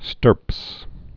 (stûrps)